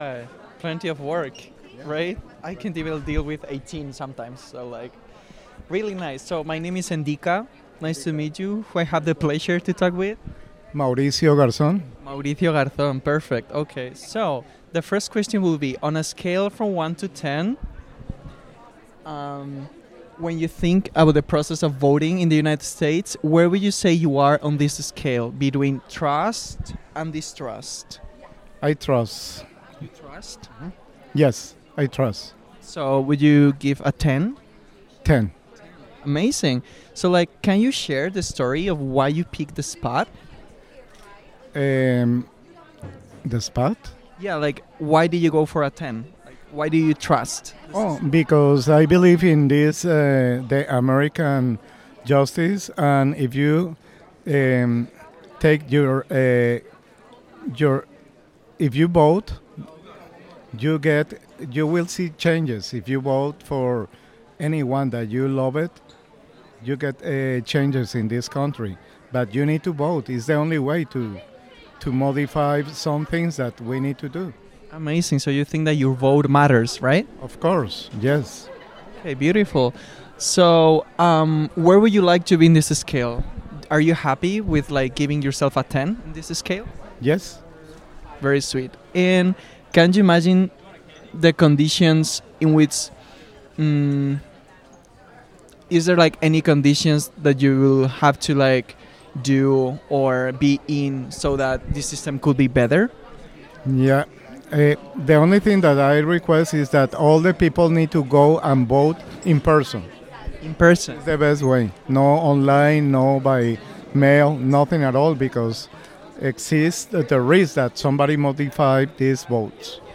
Location UWM Student Union